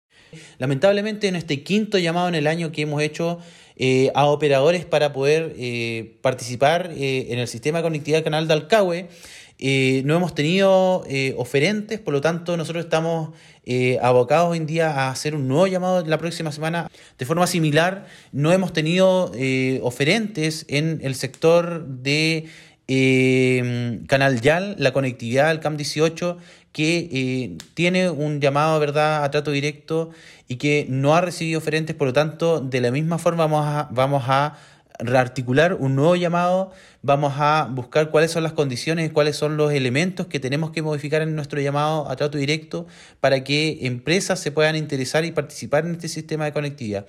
Por ello es que se abrió un nuevo concurso desde la secretaría regional ministerial de Transportes, informó el seremi del ramo, Pablo Joost, destacando que esto significará un aumento en los montos que se disponen para los armadores.